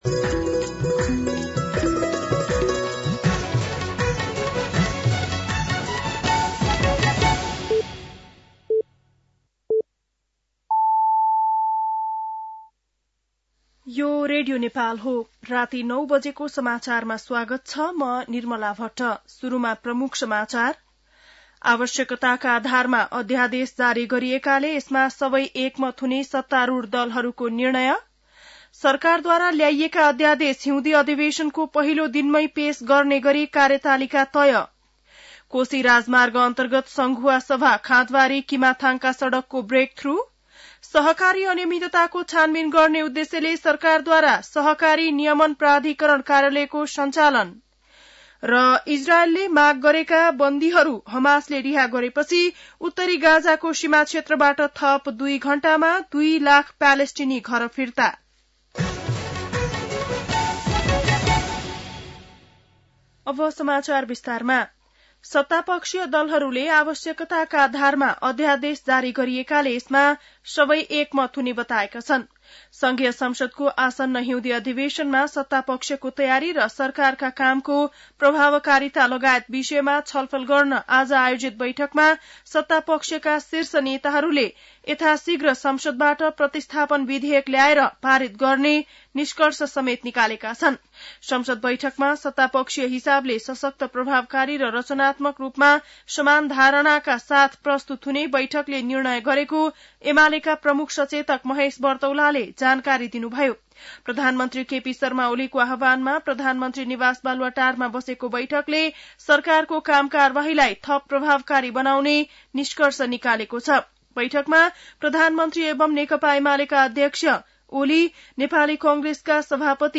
बेलुकी ९ बजेको नेपाली समाचार : १५ माघ , २०८१